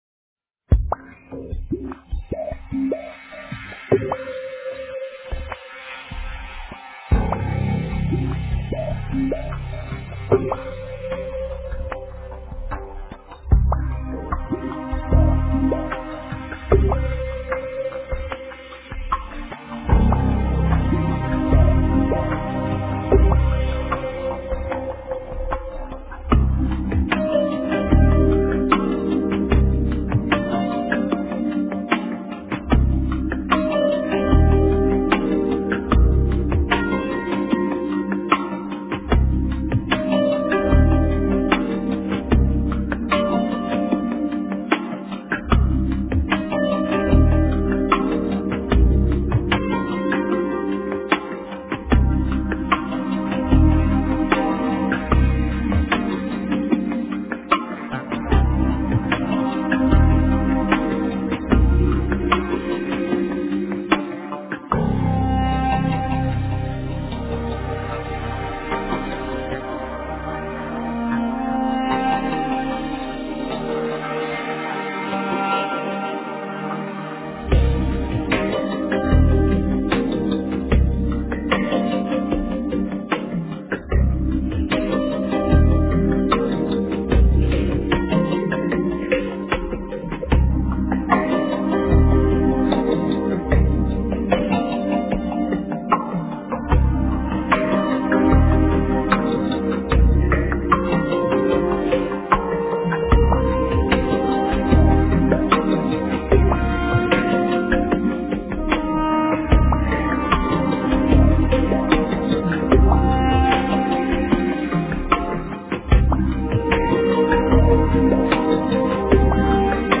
Babel巴别塔--禅定音乐 冥想 Babel巴别塔--禅定音乐 点我： 标签: 佛音 冥想 佛教音乐 返回列表 上一篇： Silent Joy--Jane Winther 下一篇： 云游四海--王俊雄 相关文章 药师心咒--秦麦洛桑活佛&可可 药师心咒--秦麦洛桑活佛&可可...